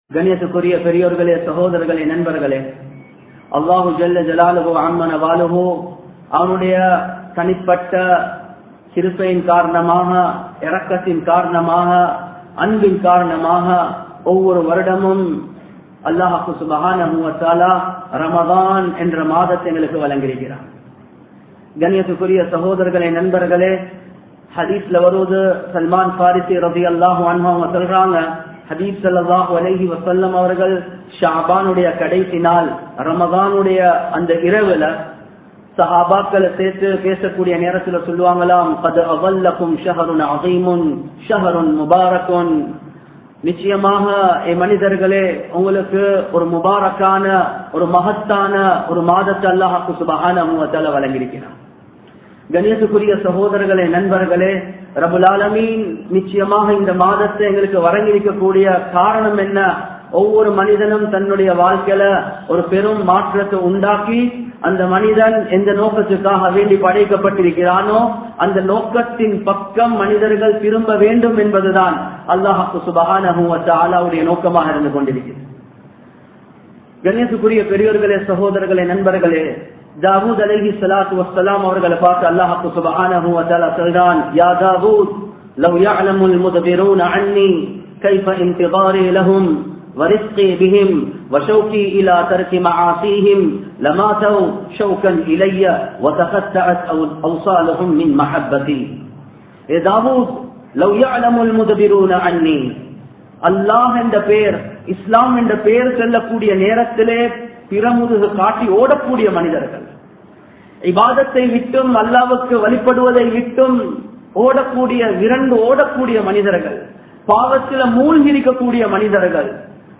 Ramalanilum Paavangalai Vida Villaiya? (ரமழானிலும் பாவங்களை விடவில்லையா?) | Audio Bayans | All Ceylon Muslim Youth Community | Addalaichenai
Colombo 11, Samman Kottu Jumua Masjith (Red Masjith)